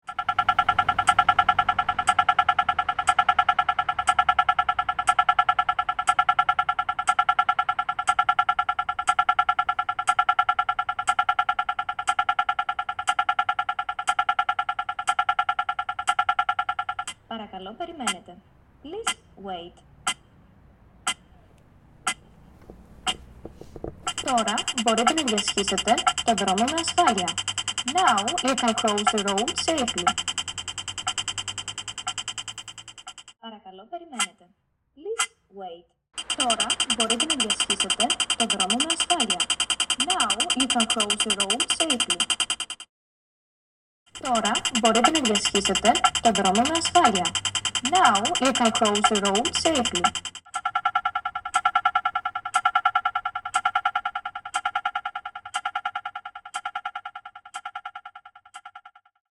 Traffic light, Athens
The Athenian traffic light that talks outside of Kallimarmaro stadium. Supposed to replace the old ones for accessibility purposes.